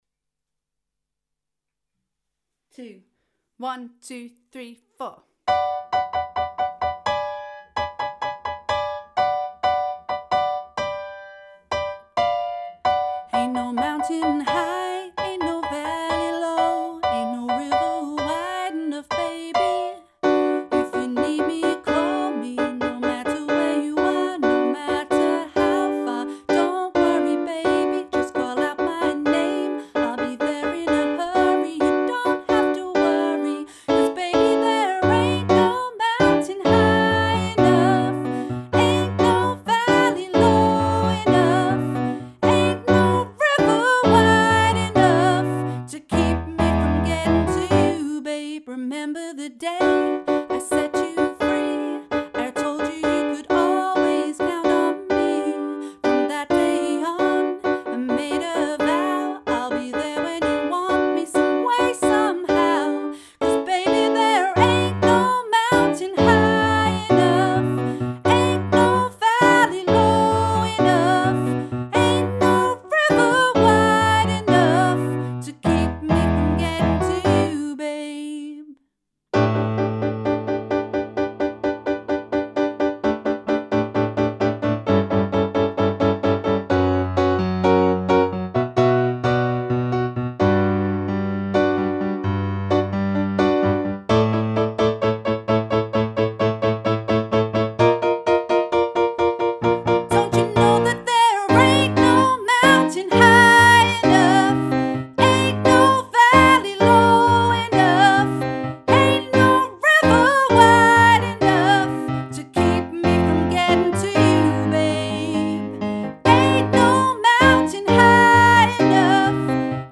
Performance Track